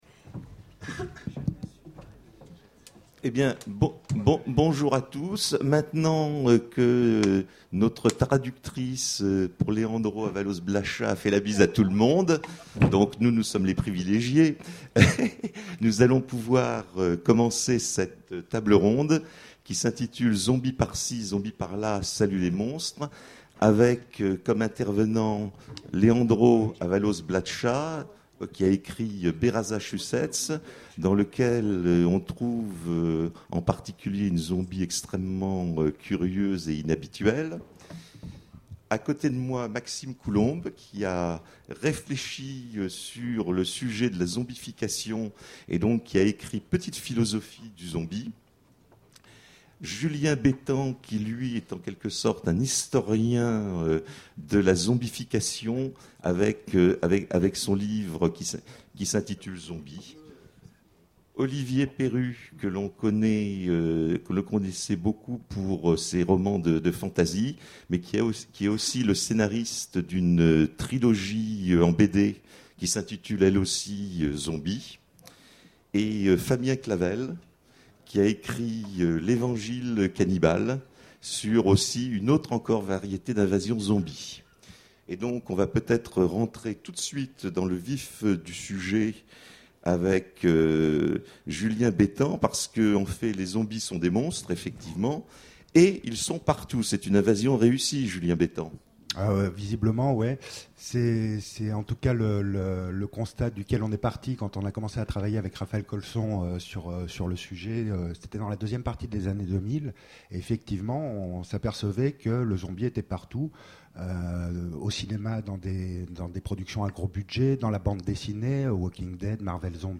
Imaginales 2014 : Conférence Zombies par-ci... Zombies par-là...